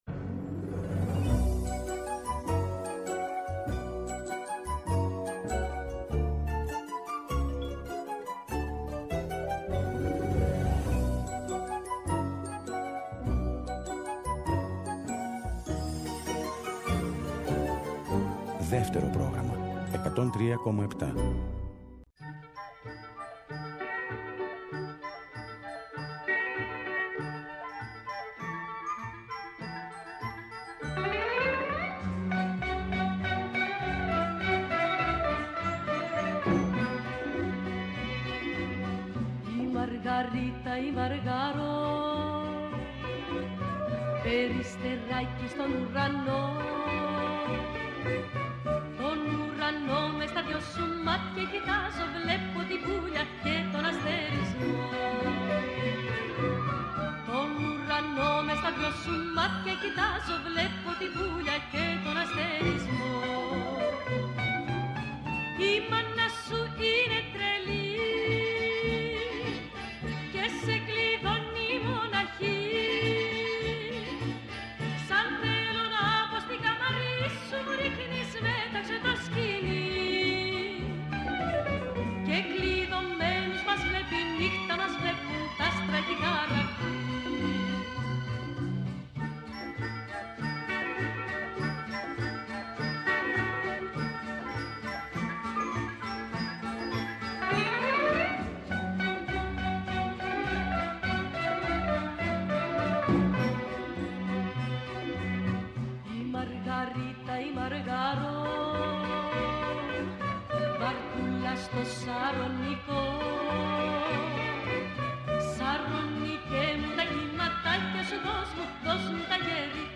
συνομιλεί τηλεφωνικά
ΣΥΝΕΝΤΕΥΞΕΙΣ